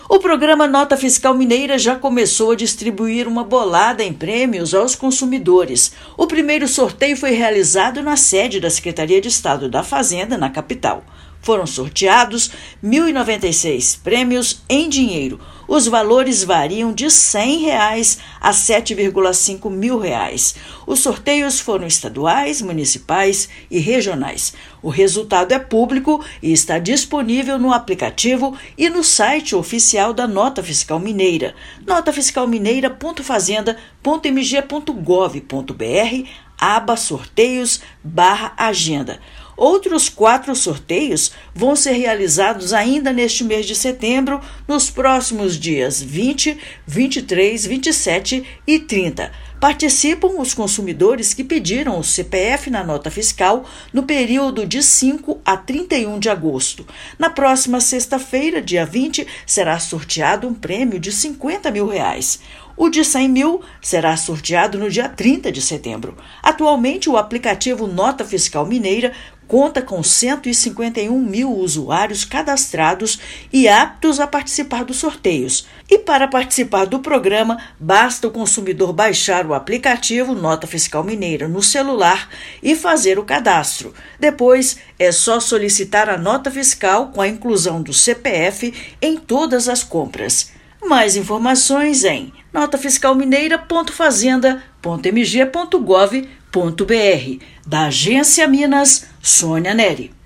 [RÁDIO] Nota Fiscal Mineira entrega mais de mil prêmios em dinheiro no primeiro sorteio
Extração ocorreu em 16/9 e lista com o resultado já está disponível no aplicativo e no site oficial do programa. Ouça matéria de rádio.